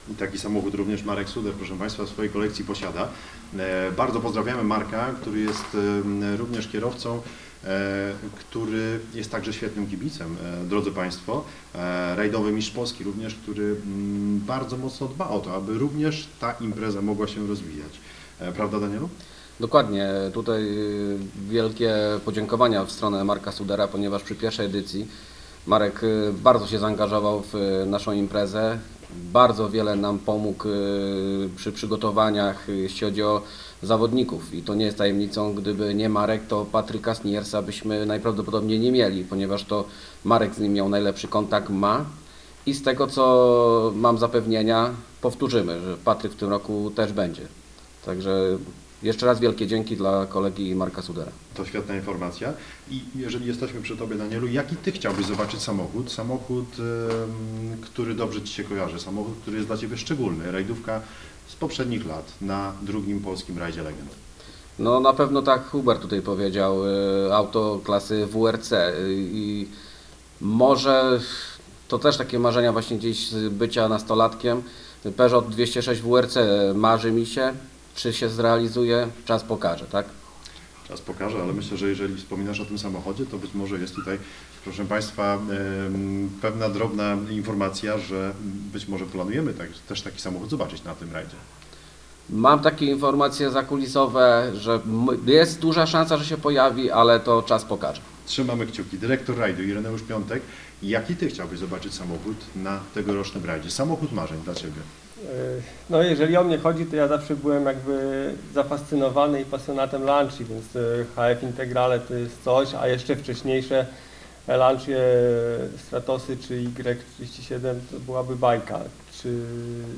16 czerwca br. w hotelu Polanica Resort SPA odbyła się konferencja prasowa dotycząca 2. Polskiego Rajdu Legend, który rozgrywany będzie 29 i 30 sierpnia na terenie sześciu gmin powiatu kłodzkiego.